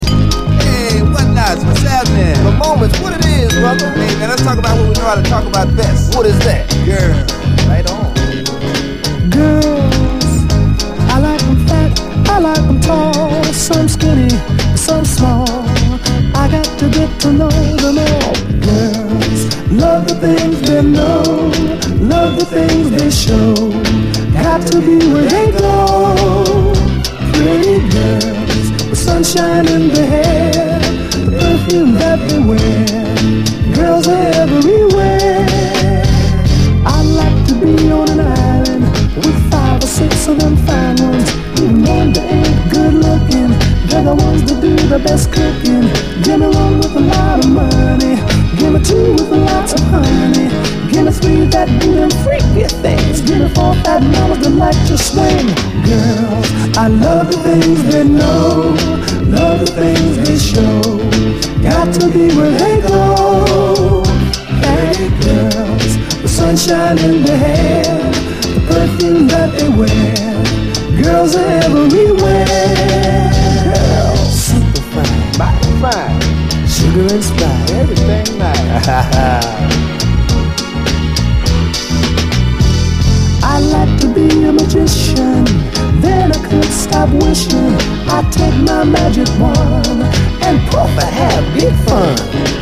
SOUL, 70's～ SOUL, 7INCH